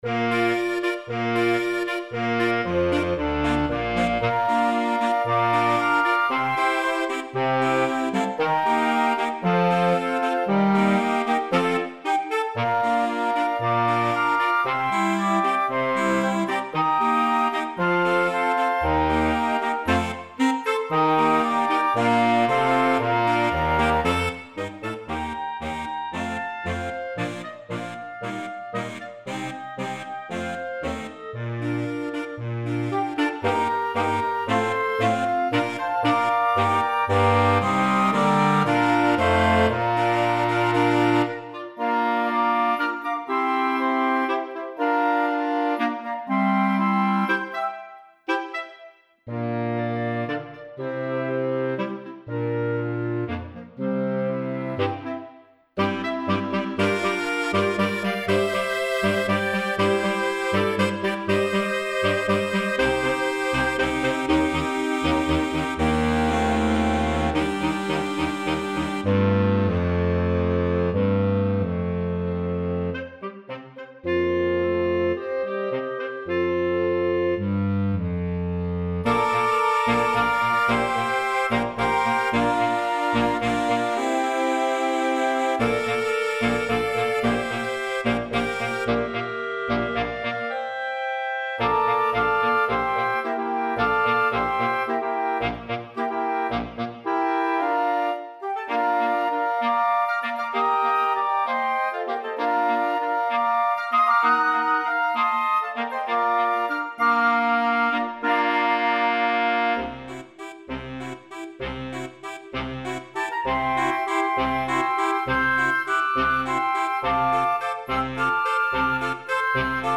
Holzbläser, digital instruments.